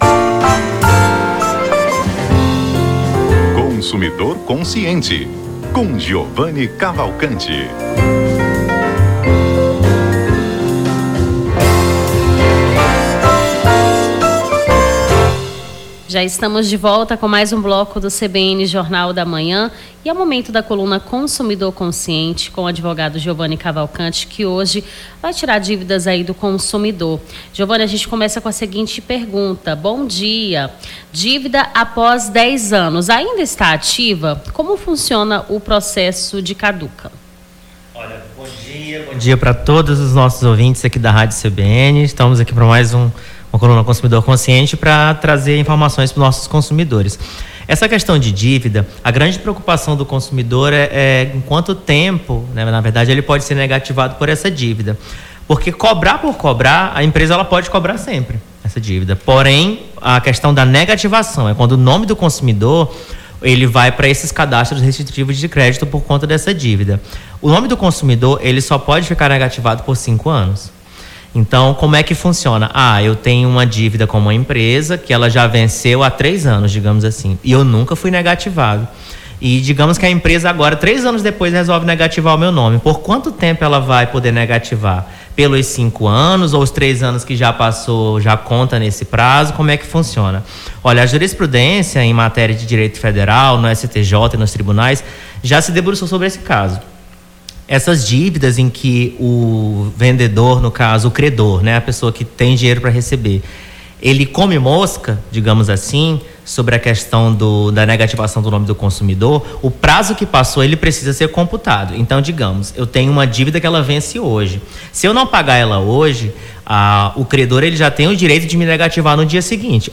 Consumidor Consciente: advogado esclarece dúvidas sobre o direito do consumidor